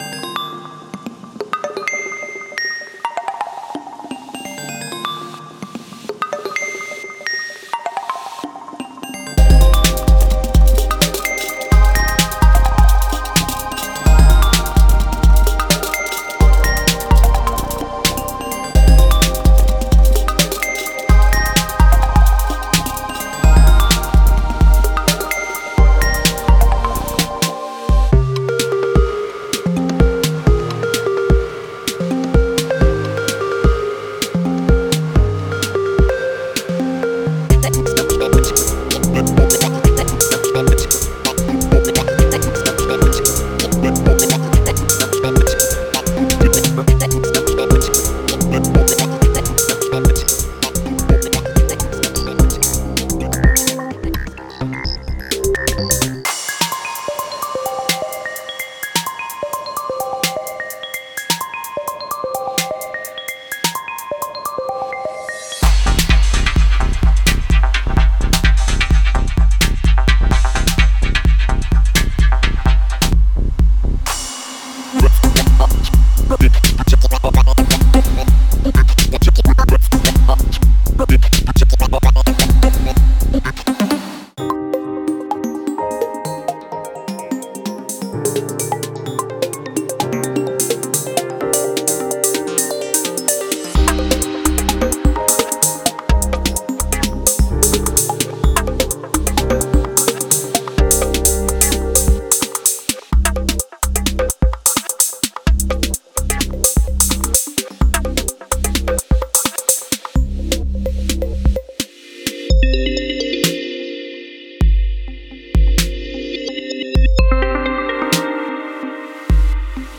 Genre:IDM
デモサウンドはコチラ↓